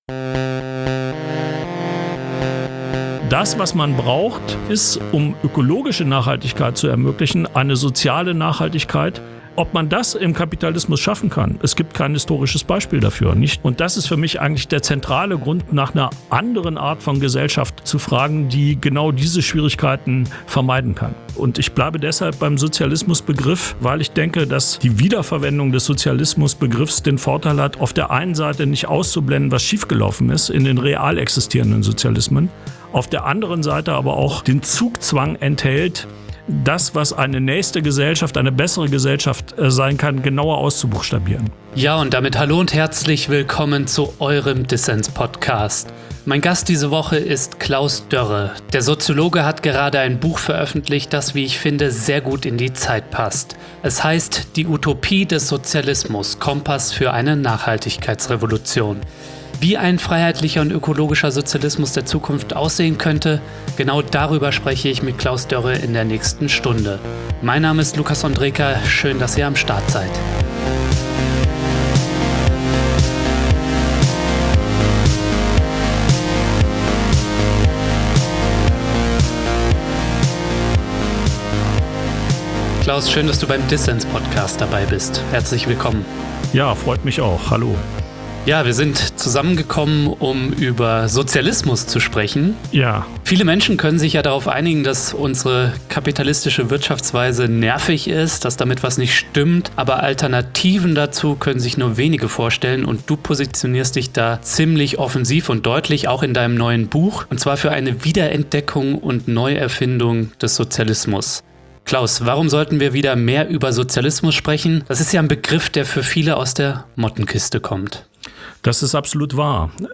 Ein Gespräch über Klimagerechtigkeit und den Sozialismus der Zukunft.